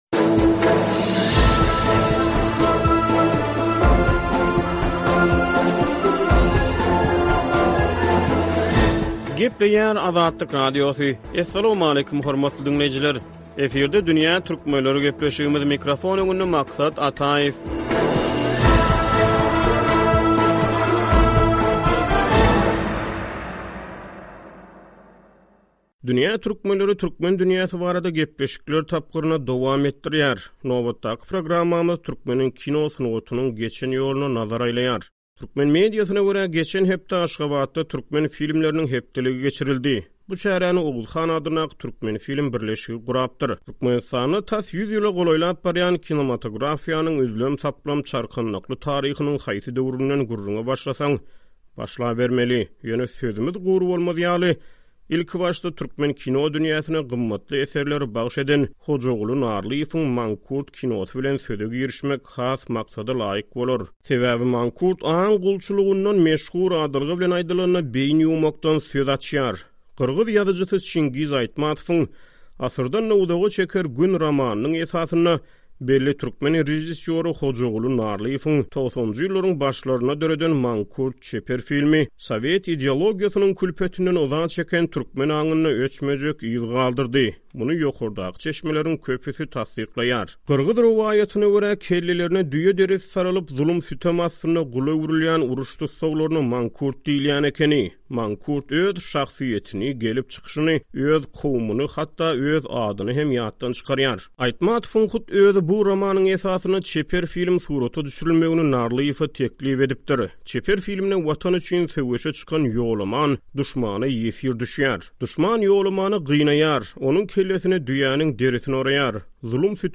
Aşgabat kinofestiwalynyň fonunda türkmen kinosynyň geçen ýoly barada gysga söhbet